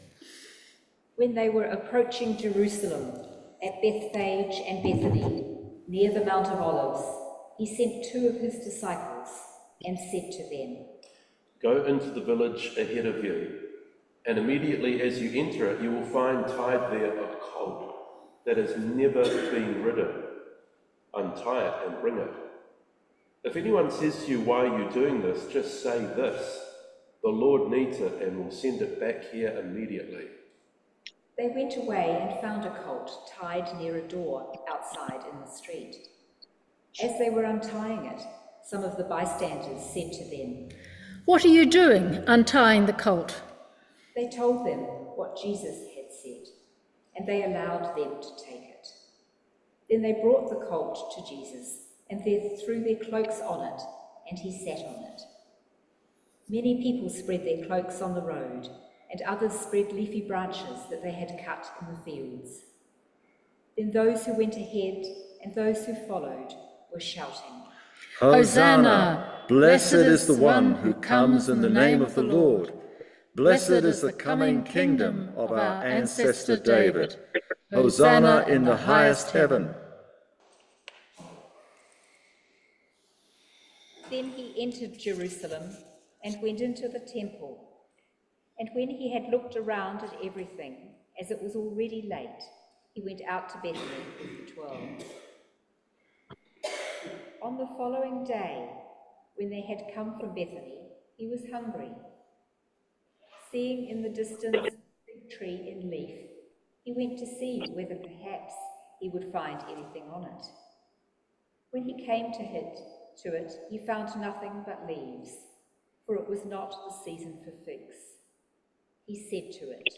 This Sunday we had a narration of Mark 11:1-14:11, covering Jesus’ entry into Jerusalem and his interactions and actions there, leading up to the Last Supper.
This recording has short breaks between sections where in church we had songs.